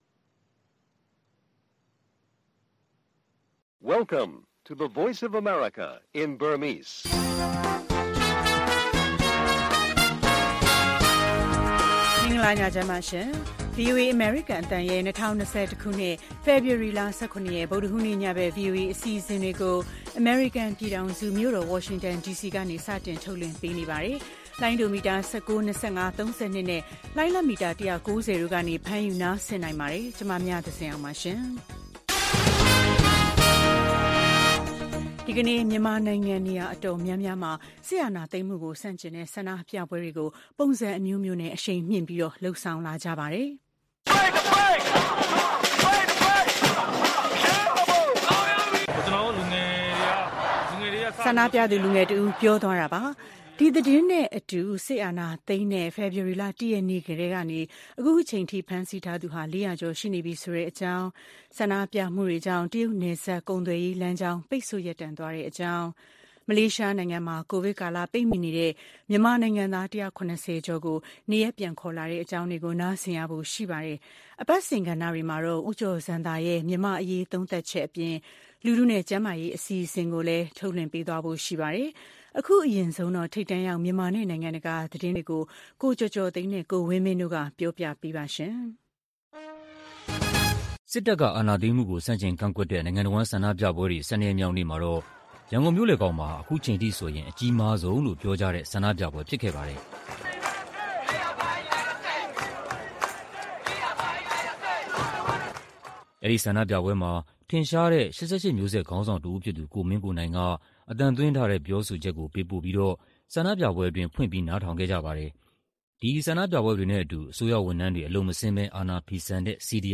ဗုဒ္ဓဟူးည ၉း၀၀ - ၁၀း၀၀ ရေဒီယိုအစီအစဉ်။